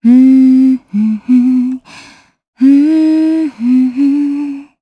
Xerah-Vox_Hum_jp.wav